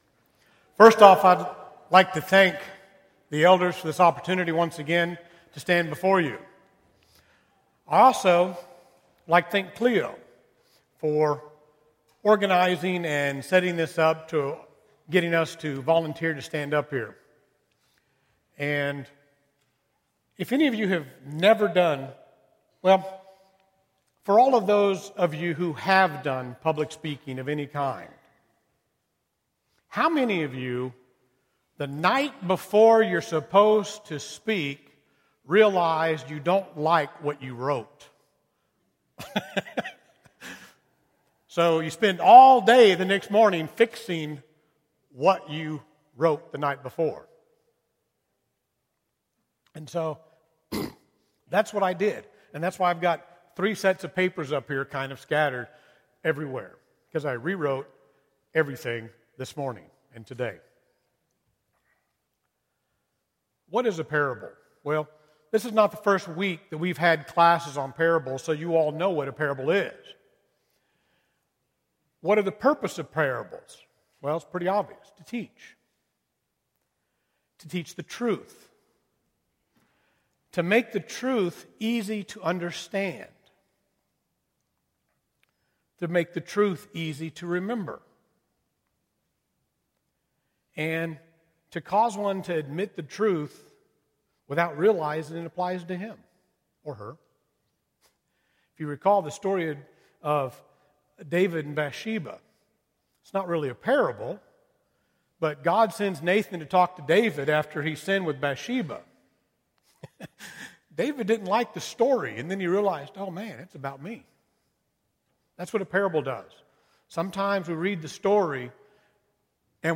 A Study of Selected Parables (6 of 7) – Bible Lesson Recording